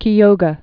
(kē-ōgə), Lake